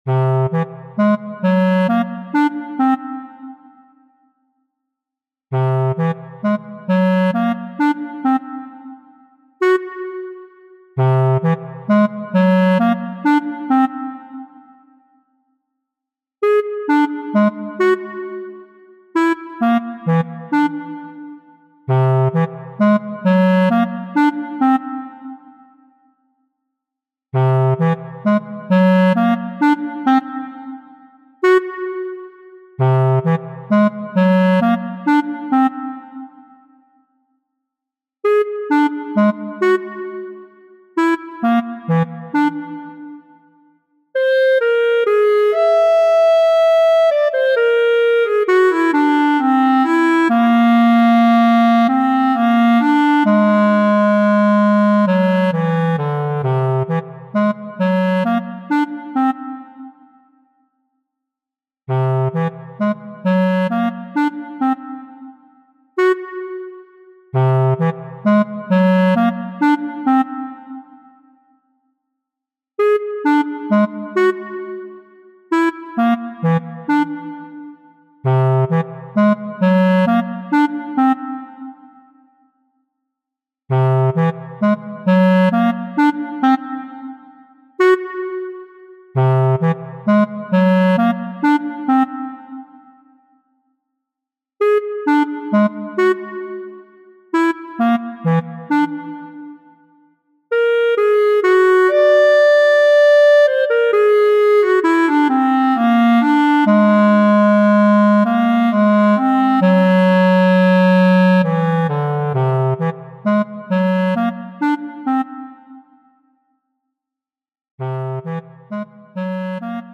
タグ: ダラダラ 不思議/ミステリアス 音少なめ/シンプル コメント: ダウナー系キノコキャラをイメージした楽曲。